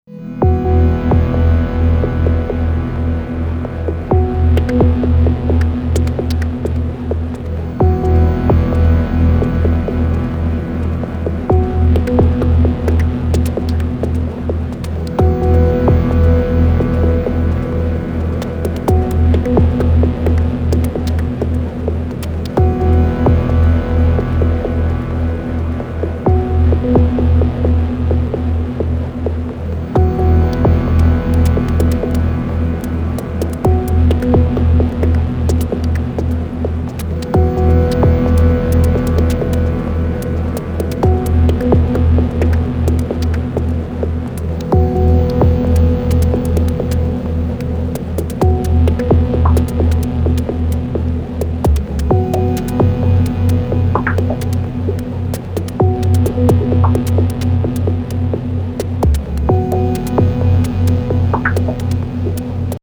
Some MD GND machines only sounds again.